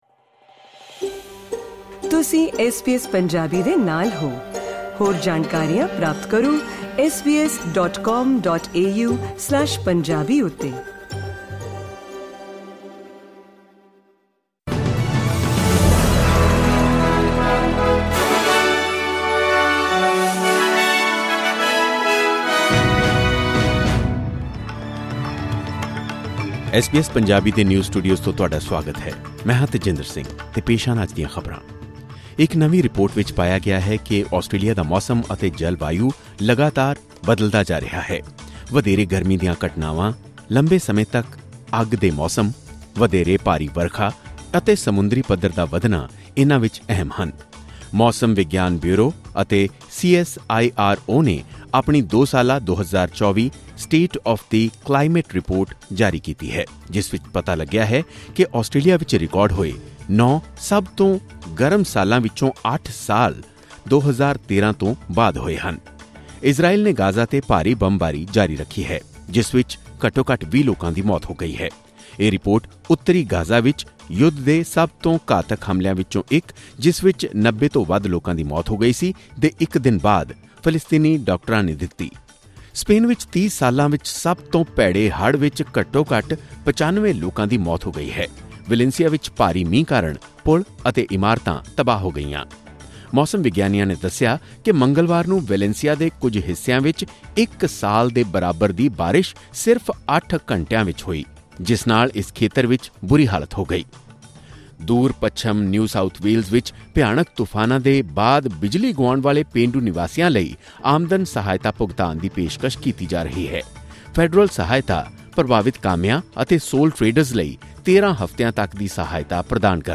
ਐਸ ਬੀ ਐਸ ਪੰਜਾਬੀ ਤੋਂ ਆਸਟ੍ਰੇਲੀਆ ਦੀਆਂ ਮੁੱਖ ਖ਼ਬਰਾਂ: 31 ਅਕਤੂਬਰ, 2024